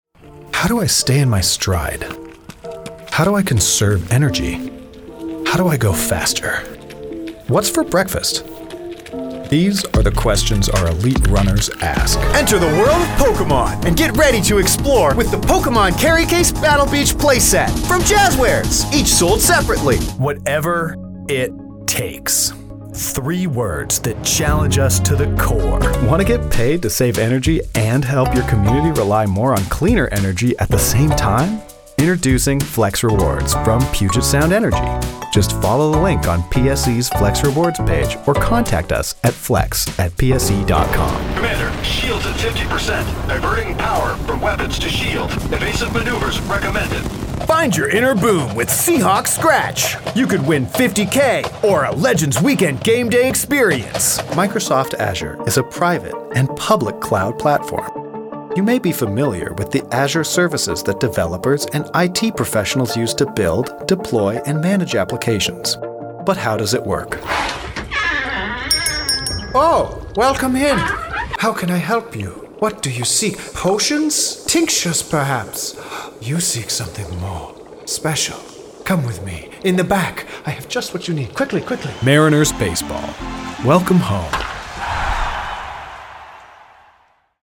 90 Second Mixed Demo
English - Midwestern U.S. English
British RP, Austrailian, New Zealand, US Southern, US Midwestern, US West Coast
Character Voice
Commercial